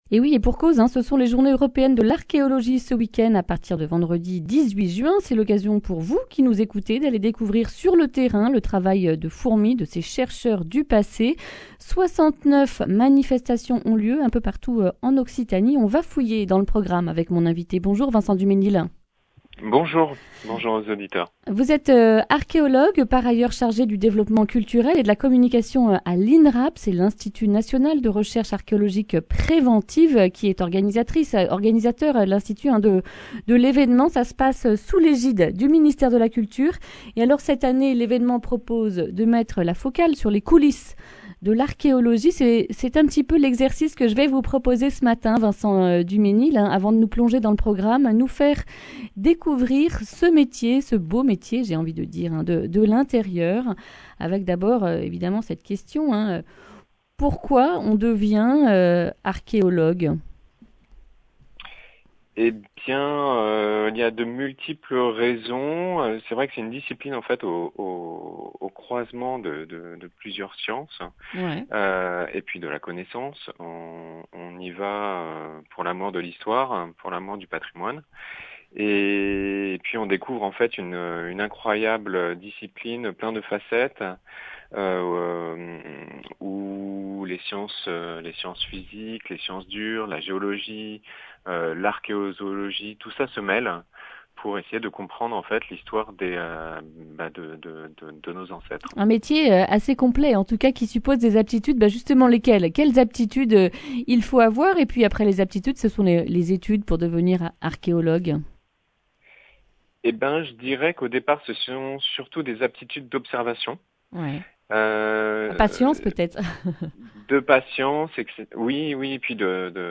Accueil \ Emissions \ Information \ Régionale \ Le grand entretien \ Journées européennes de l’archéologie : demandez le programme en Occitanie !